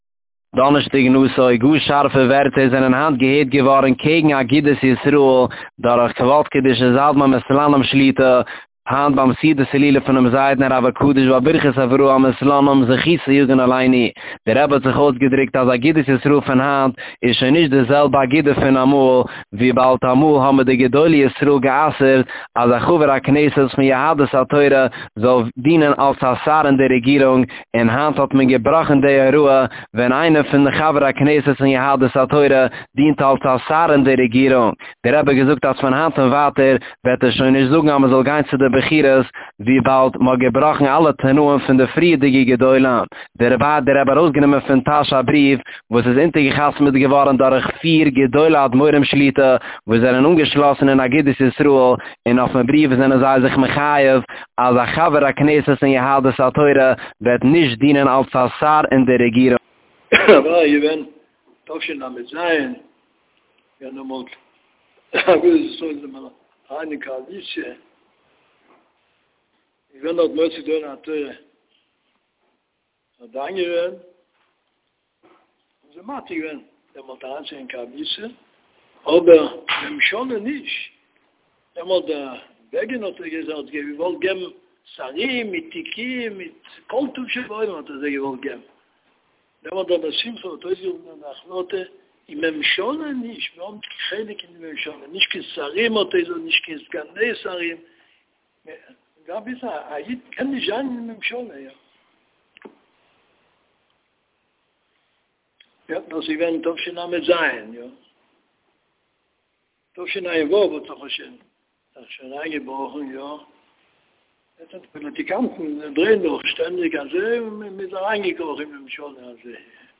גאר שארפע ווערטער קעגן אגודת ישראל זענען היינט געהערט געווארן פון כ"ק אדמו"ר מסלאנים שליט"א, ביים סעודת הילולא פון זקינו הרה"ק בעל ברכת אברהם מסלאנים זי"ע.